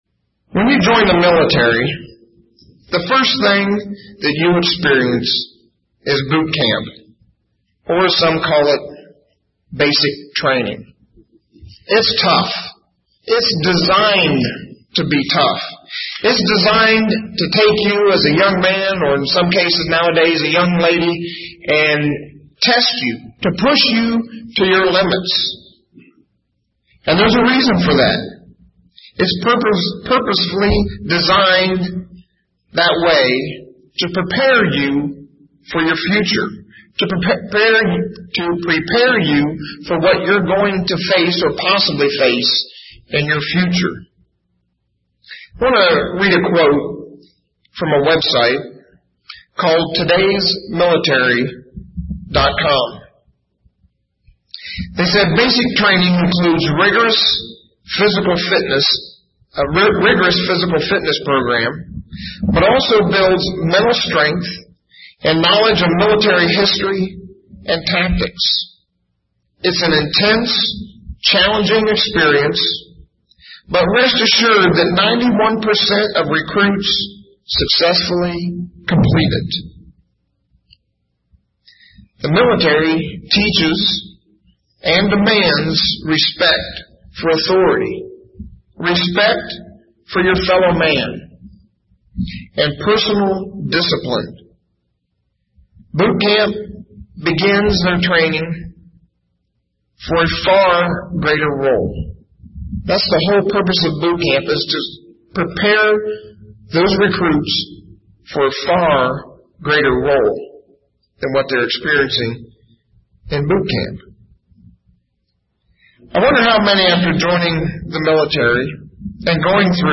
Given in Indianapolis, IN Ft. Wayne, IN
UCG Sermon Studying the bible?